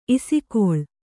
♪ isikoḷ